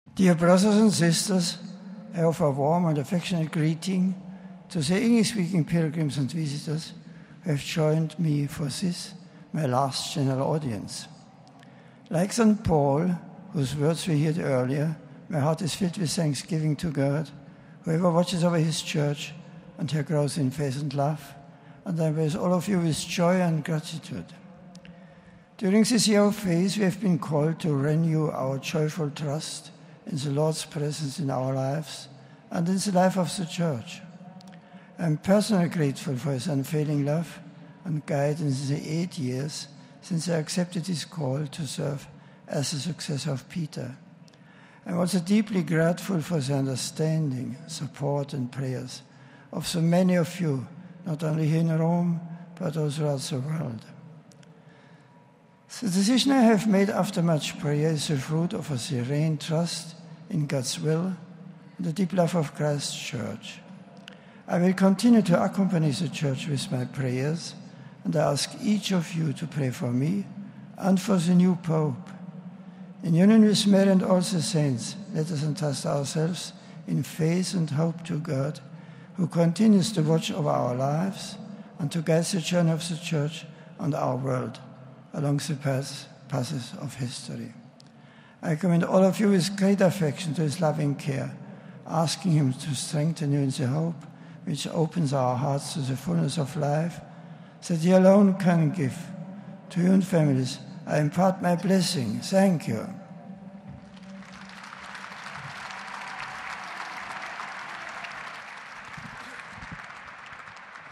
Pope Benedict XVI's remarks in English during his final General Audience: